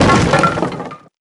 crash_table-04.wav